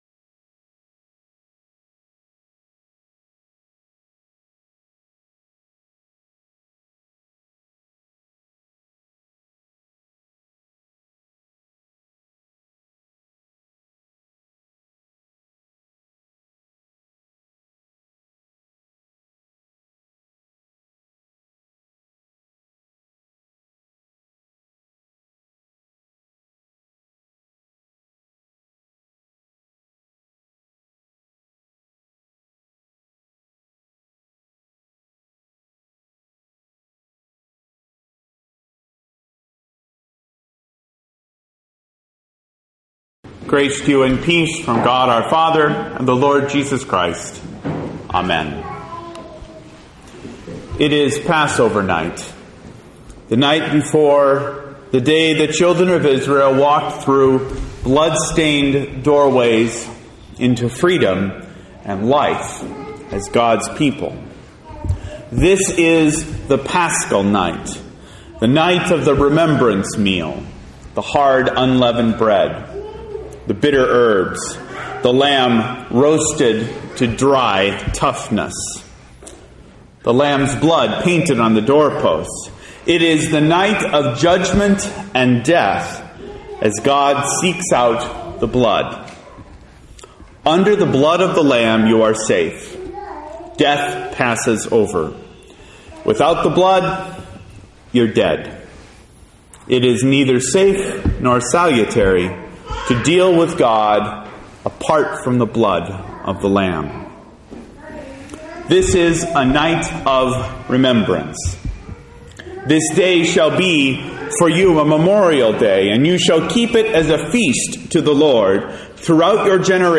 This sermon is a revision of the CPH series sermon for this year on the Words of Christ from the cross.